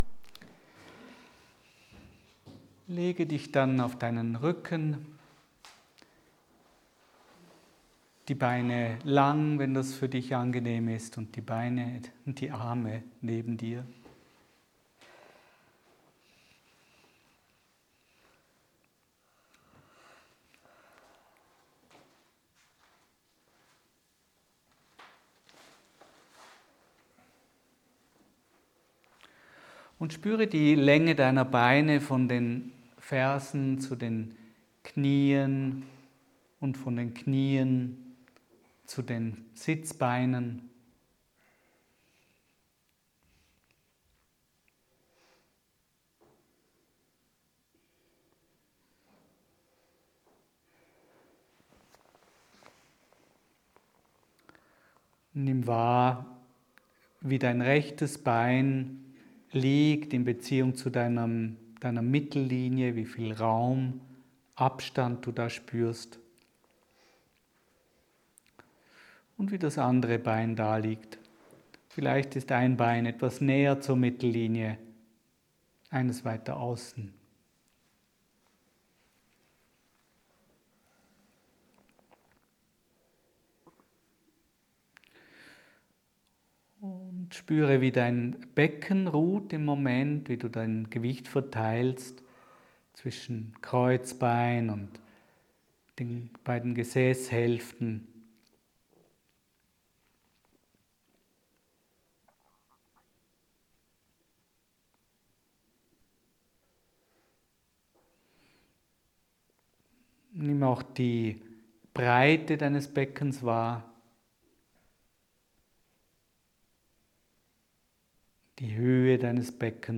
Quelle: AY 456 Die Lektion ist ein Live-Mitschnitt meiner Gruppenkurse in Feldenkrais Bewusstheit durch Bewegung.